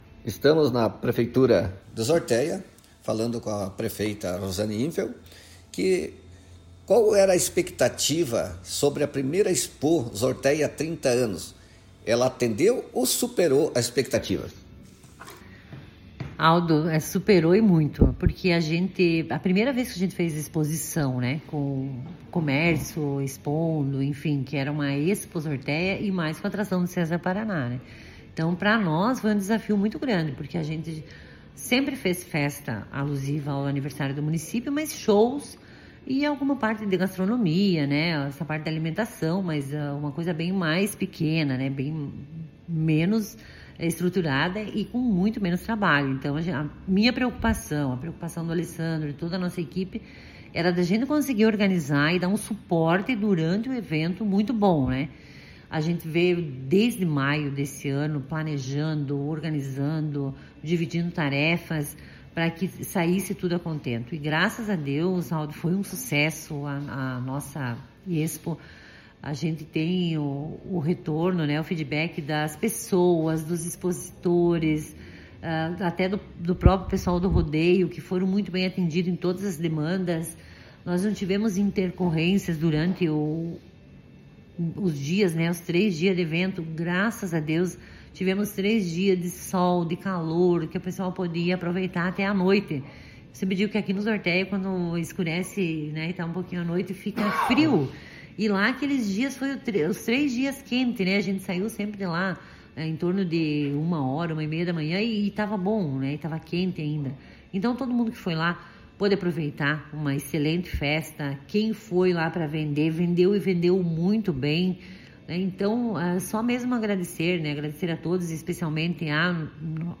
Prefeita_de_Zortéa,_Rosane_Infeld..mp3